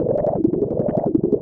RI_ArpegiFex_170-02.wav